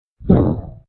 Boss_COG_VO_grunt.ogg